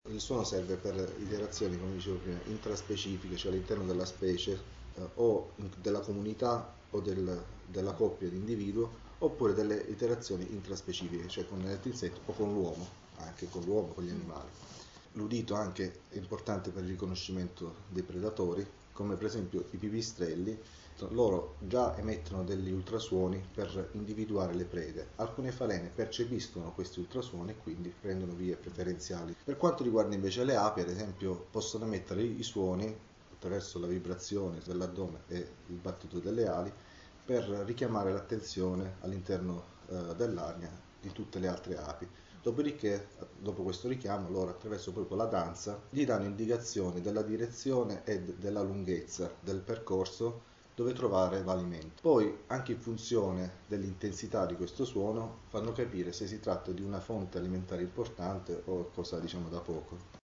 Le api, ad esempio, possono emettere suoni attraverso la vibrazione dell’addome e il battito delle ali per richiamare l’attenzione all’interno dell’arnia; in seguito, dopo il richiamo, attraverso una danza danno indicazioni sulla direzione e sulla lunghezza del percorso da affrontare per trovare il cibo e, anche in funzione dell’intensità di questo suono, esse fanno capire se si tratta di una fonte alimentare importante o meno.